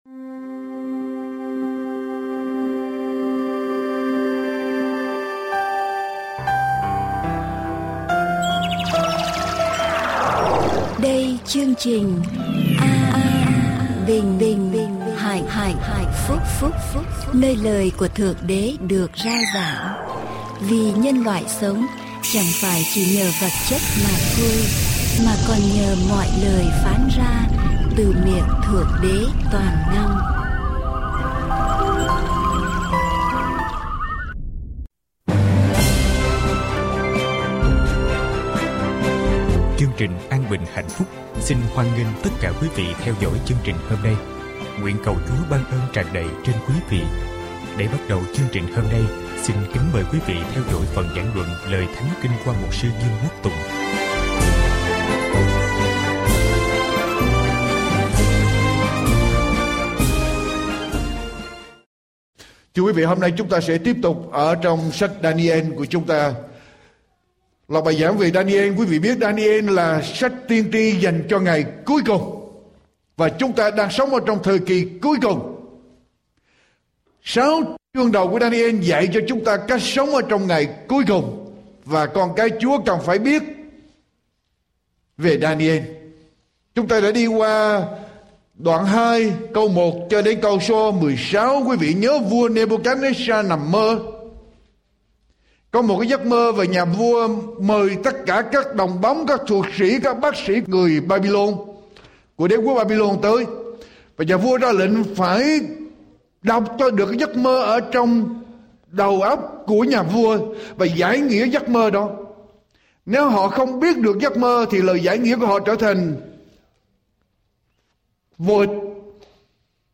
Adventist Vietnamese Sermon